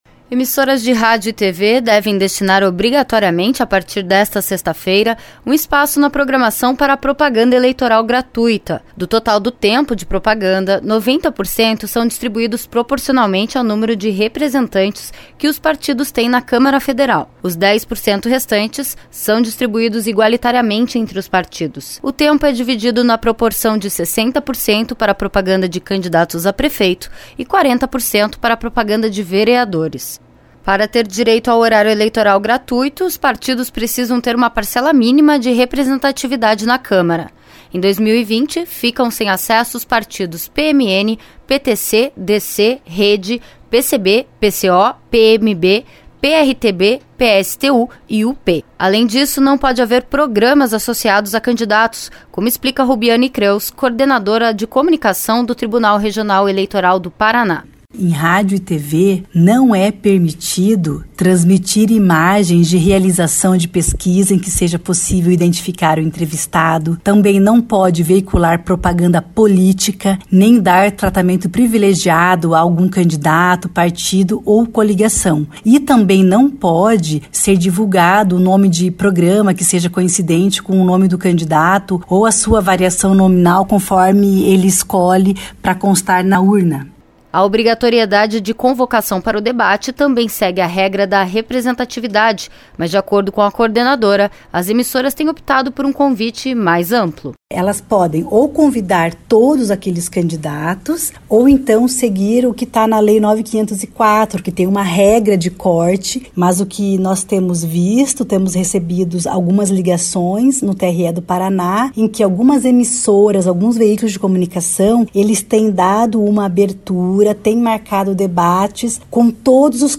A reportagem traz as regras de divulgação em rádio e TV para estas eleições.